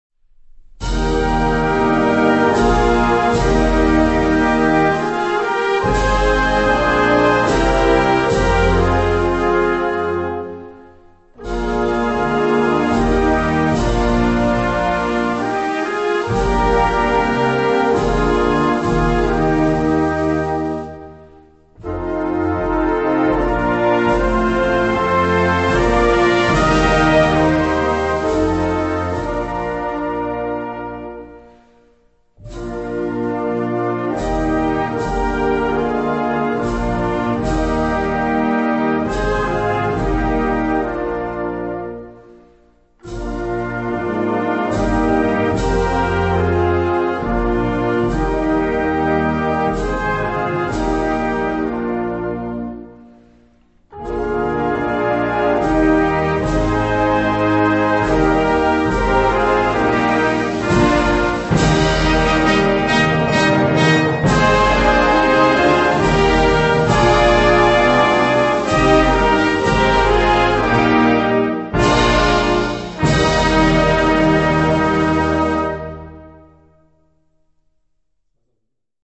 Gattung: Bundeshymne
Besetzung: Blasorchester